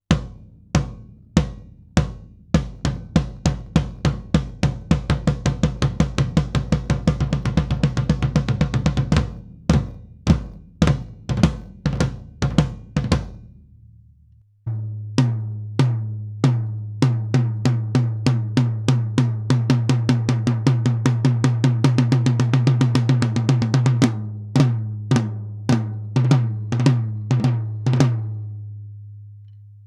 Schlagfell: Amba coated, Reso CS Black dot. Abstand zur Trommel: rund 10 cm
Shure SM 57
sm57 und m201 heben den Anschlagsklick deutlich hervor, was mir ganz gut gefällt.